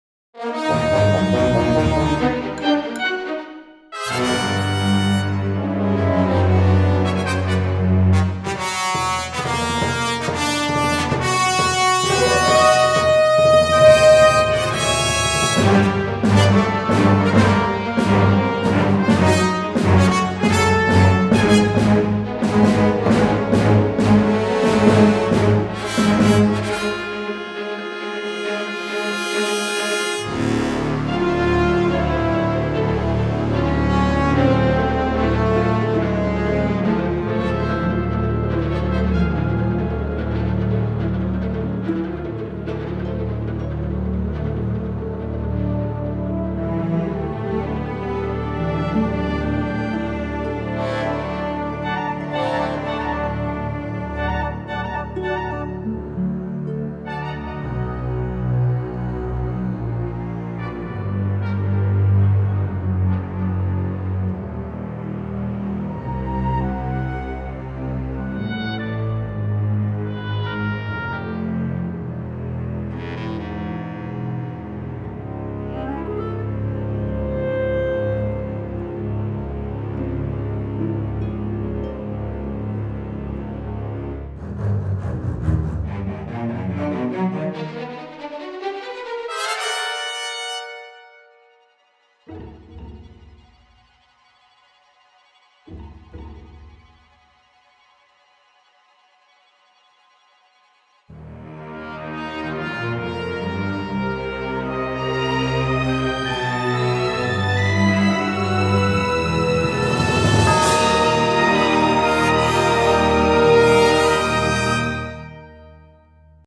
Вот моя работа, которую было решено осуществить только средствами симфонического оркестра и с "олдскульного" подхода)